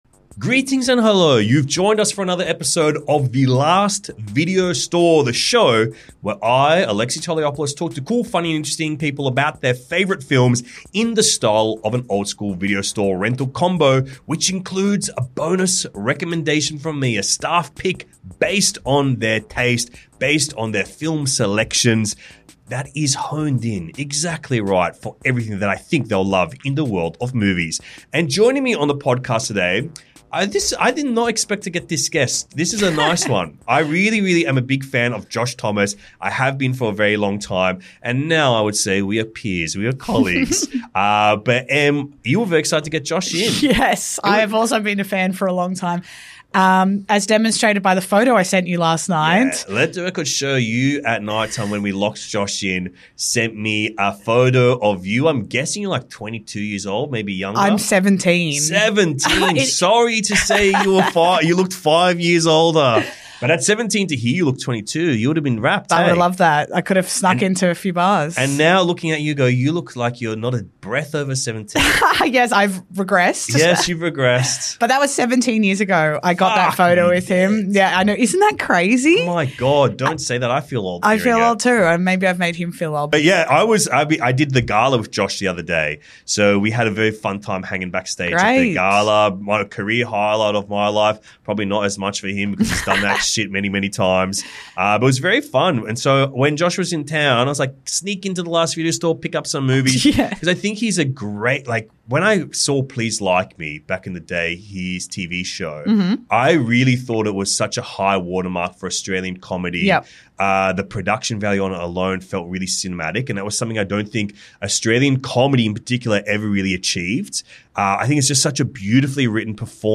Josh Thomas cracks up over RAT RACE & LOVE ACTUALLY and thinks CABERET is the best movie of all time